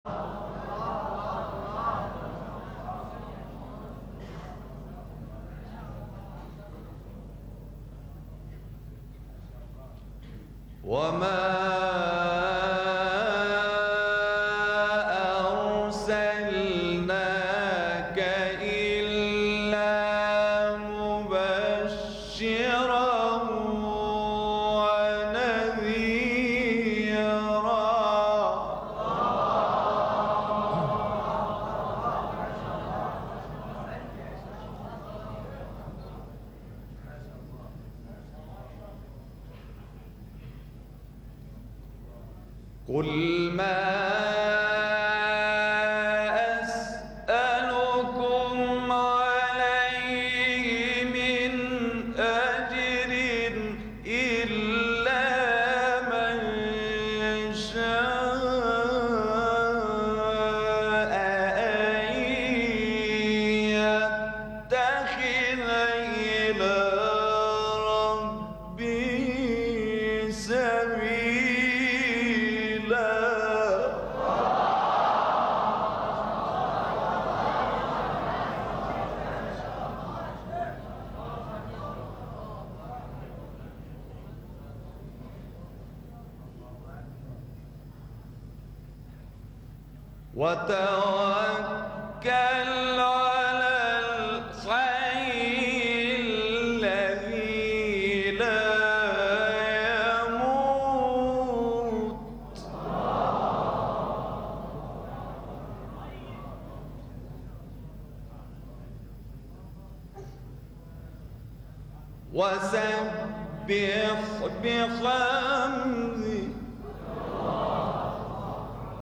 گروه فعالیت‌های قرآنی: مقاطع صوتی با صدای قاریان ممتاز کشور مصر را می‌شنوید.
مقطعی از احمد بسیونی برگرفته از تلاوت سوره فرقان ایشون در ایران سال۶۸